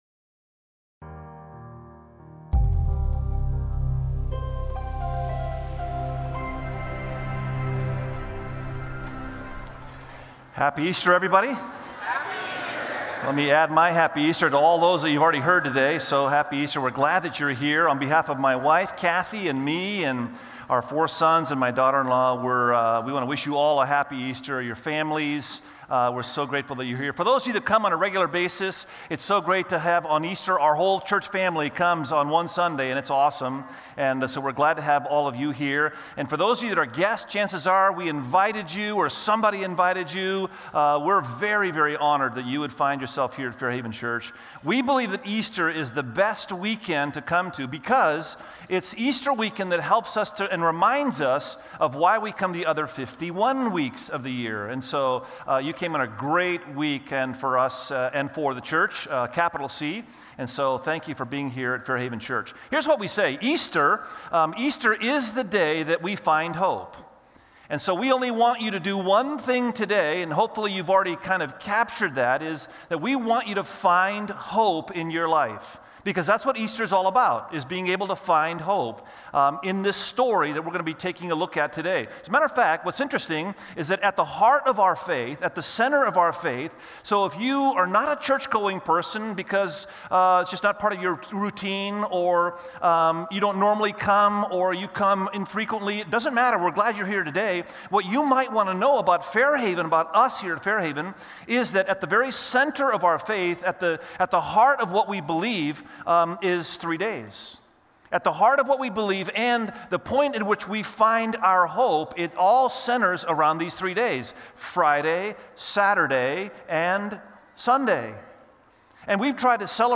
Check out Find Hope, a sermon series at Fairhaven Church.
Sermon Series